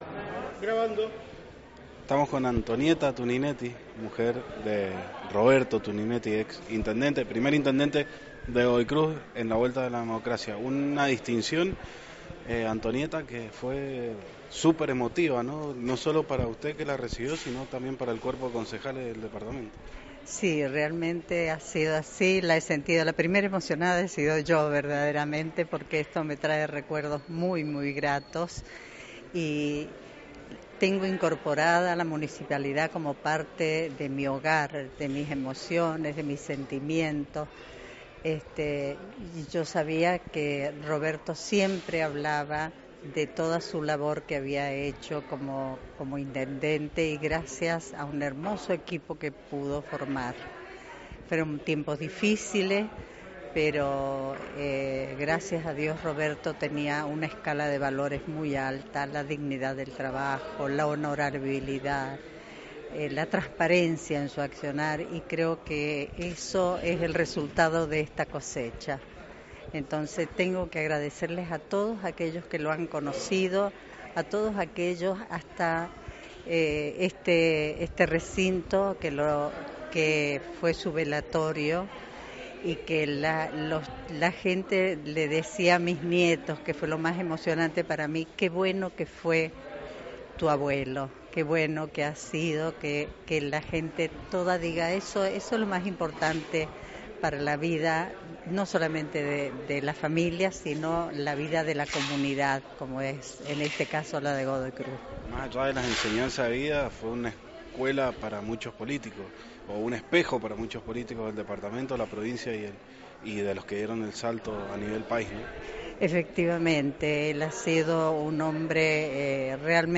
La distinción para Vecino Honorable se realizó en el HCD de Godoy Cruz.